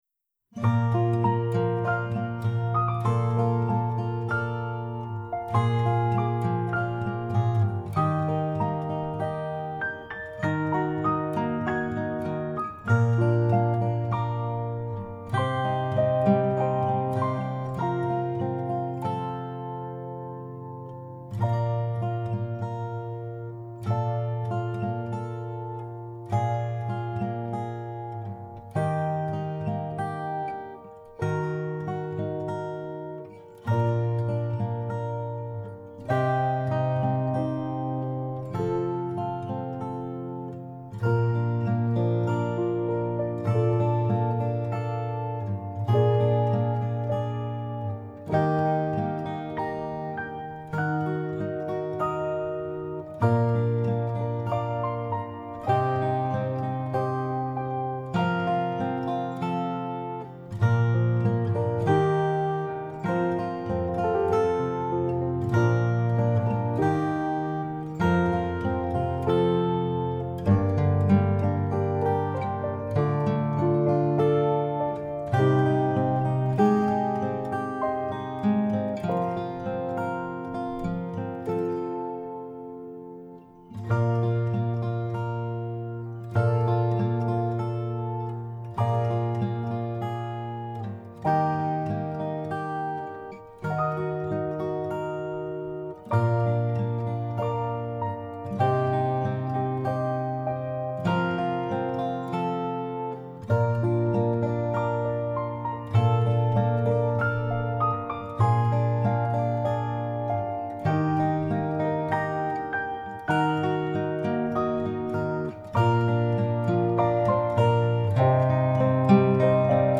Saying Goodbye Solo Piano